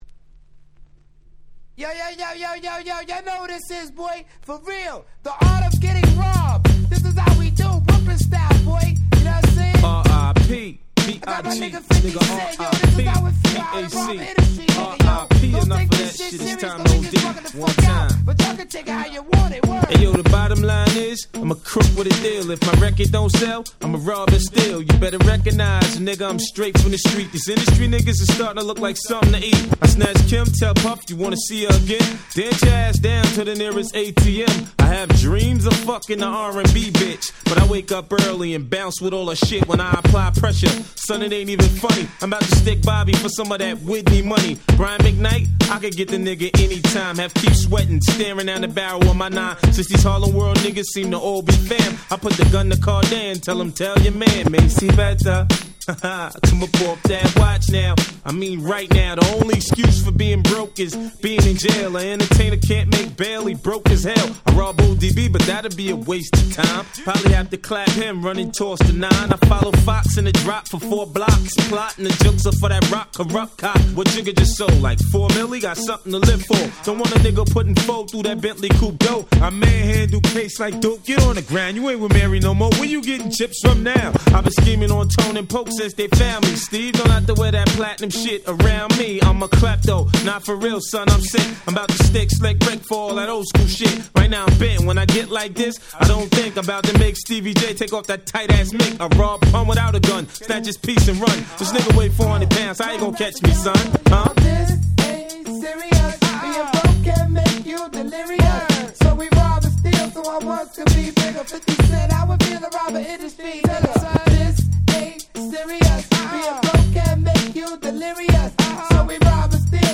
99' Smash Hit Hip Hop !!
Boom Bap